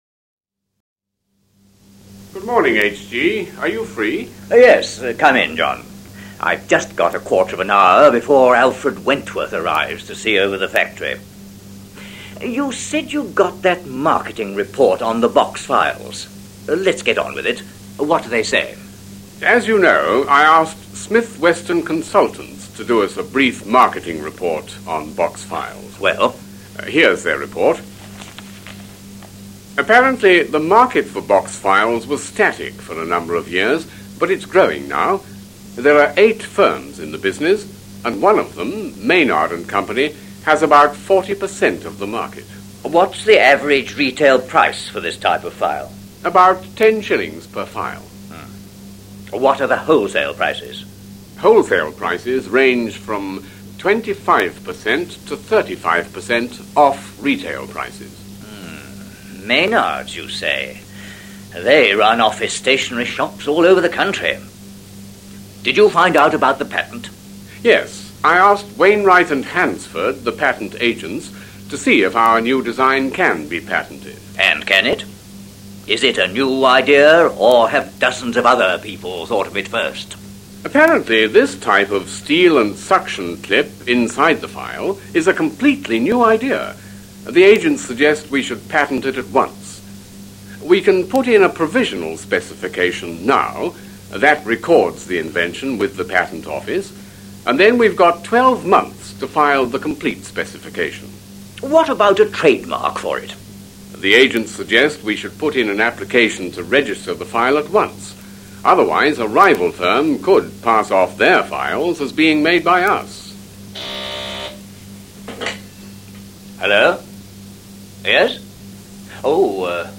conversation08.mp3